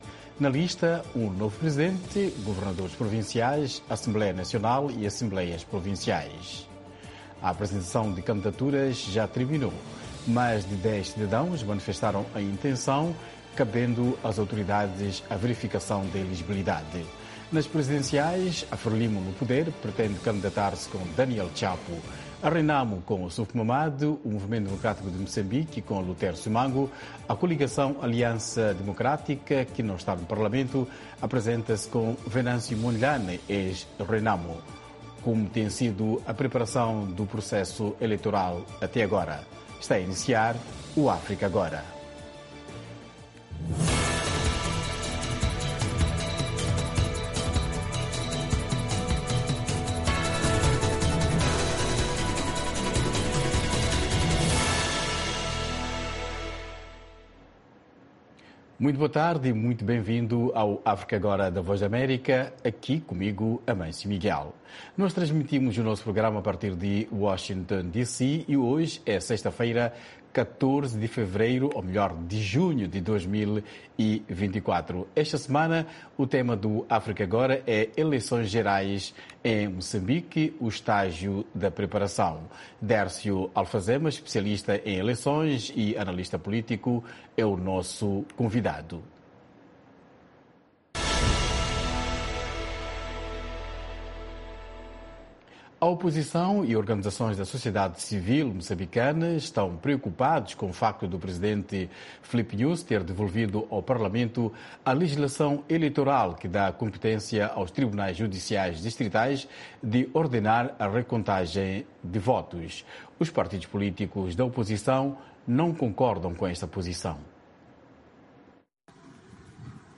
África Agora, o espaço que dá voz às suas preocupaçōes. Especialistas convidados da VOA irão comentar... com a moderação da Voz da América. Um debate sobre temas actuais da África Lusófona.